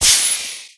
TurboBlow1.wav